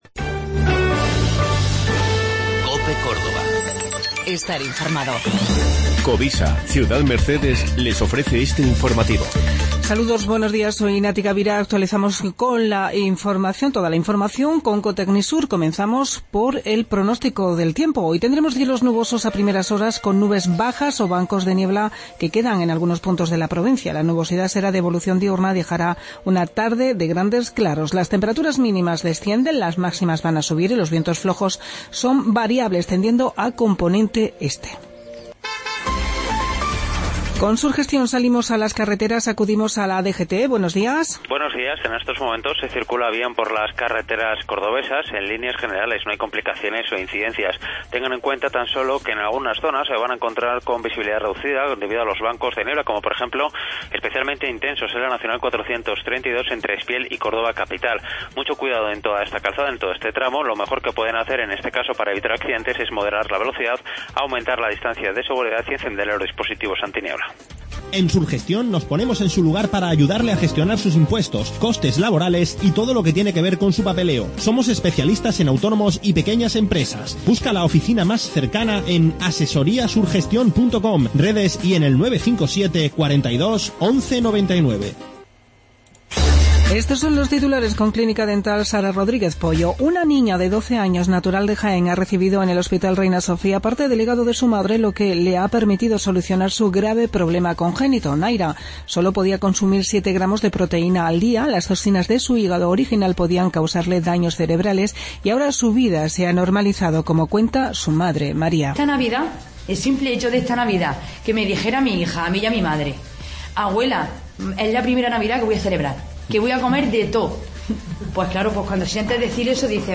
Informativo matinal 8:20. 14 de Febrero 2017